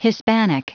Prononciation du mot hispanic en anglais (fichier audio)
hispanic.wav